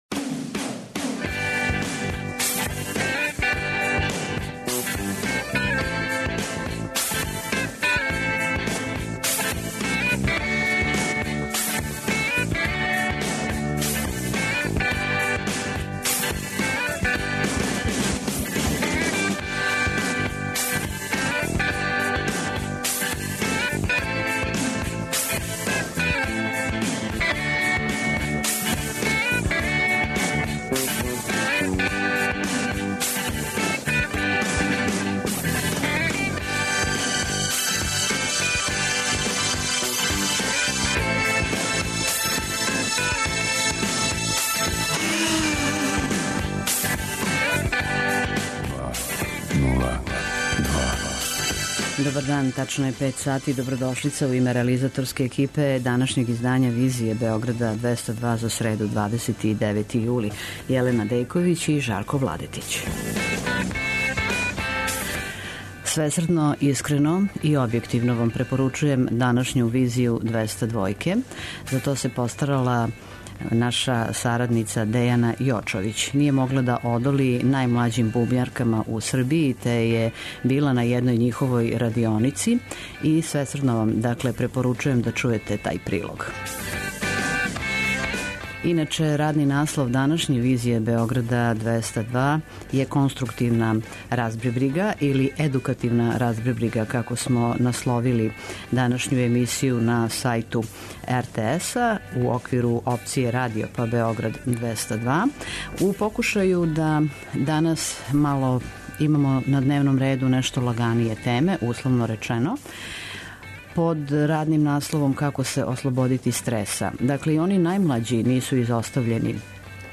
Пројекат „Femix" који афирмише женско ставаралашво у уметности иницирао је програм бубњарских радионица за девојчице у градовима Србије. Репортажа са беогрaдског окупљања ће представити најмлађе музичарке са палицама. Бојанке за одрасле су могућа одбрана од свакодневног стреса: опуштају, фокусирају и развијају евентуално заборављену креативност, слажу се психолози.